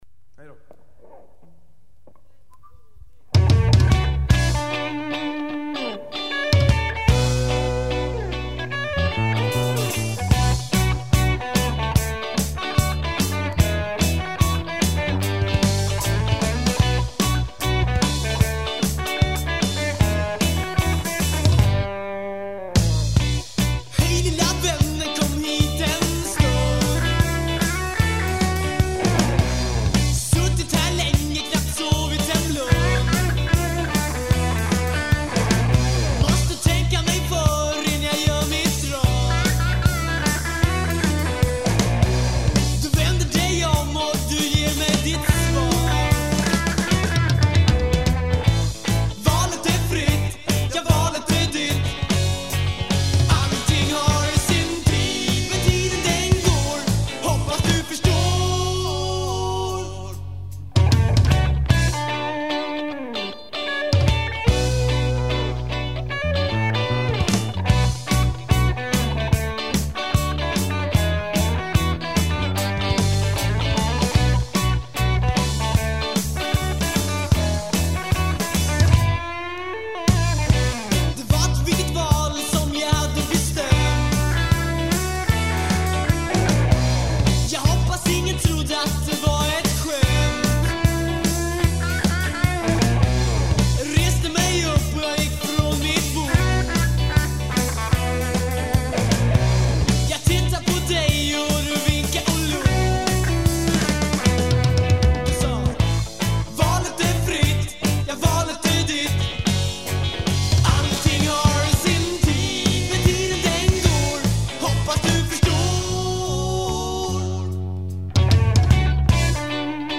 Guitar
Drums
Bass
Trumpet
Trombone
Saxophone